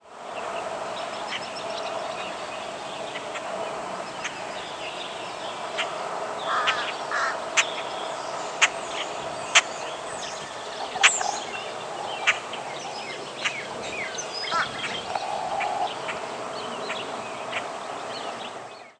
Common Grackle diurnal flight calls